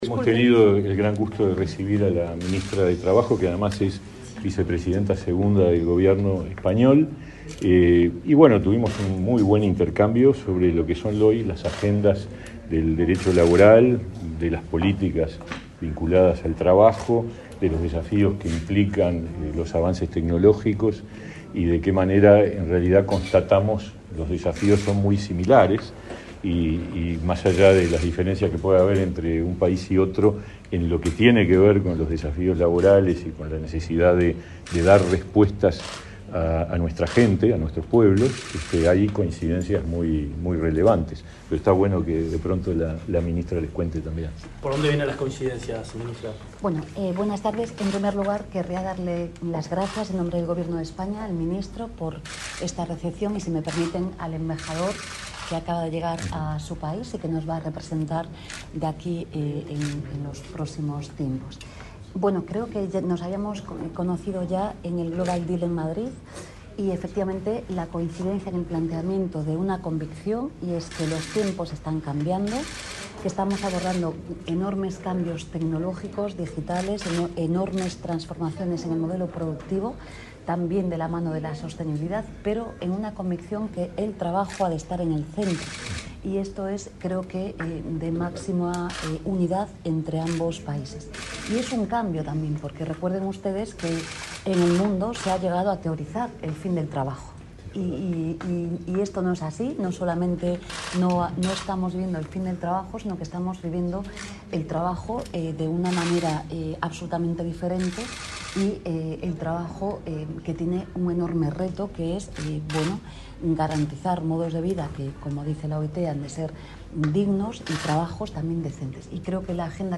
Declaraciones a la prensa del ministro de Trabajo, Pablo Mieres, y su par española, Yolanda Díaz
Este 9 de diciembre, el titular del Ministerio de Trabajo y Seguridad Social (MTSS), Pablo Mieres, se reunió con la vicepresidenta segunda del Gobierno de España y ministra de Trabajo y Economía Social, Yolanda Díaz, y con el embajador de España en Uruguay, Santiago Jiménez Martín. Tras el encuentro, ambos ministros realizaron declaraciones a la prensa.